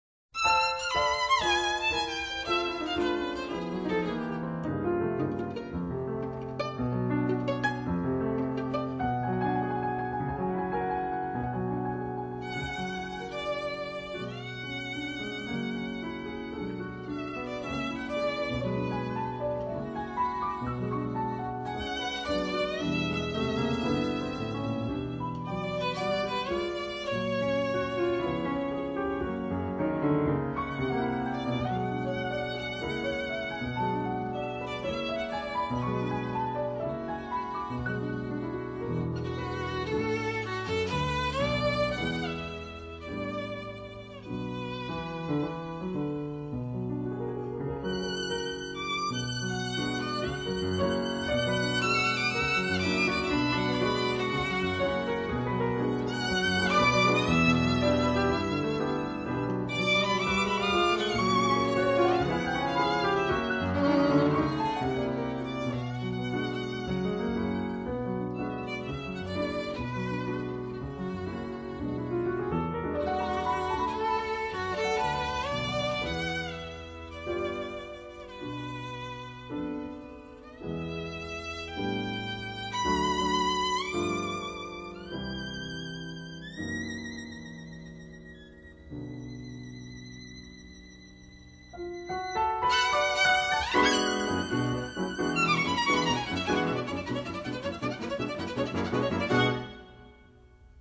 【所属类别】音乐 古典